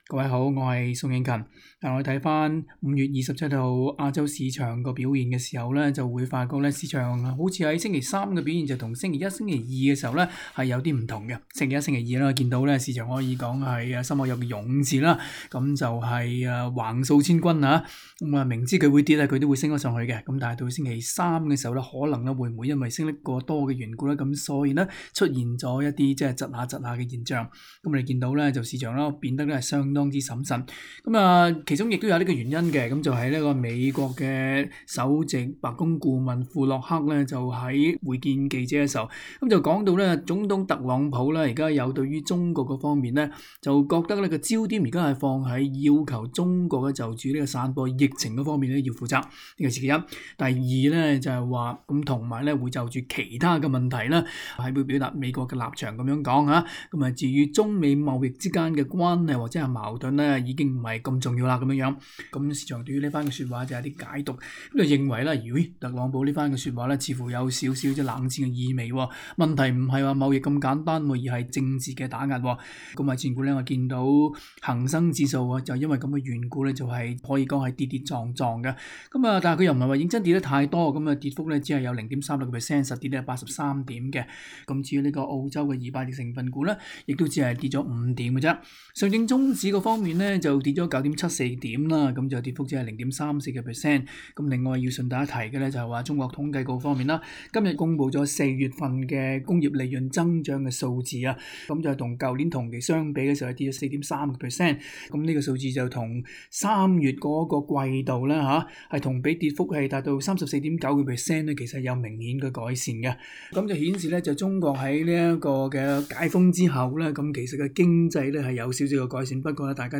Source: Getty Images SBS广东话播客 View Podcast Series Follow and Subscribe Apple Podcasts YouTube Spotify Download (22.1MB) Download the SBS Audio app Available on iOS and Android 5月27日周三， 中美摩擦预料升级，亚太区证券市场步步为营。
详情请收听录音访问内容。